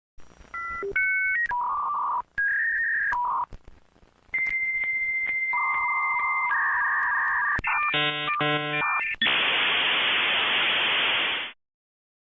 Dial Up Internet (Loading) Meme sound effects free download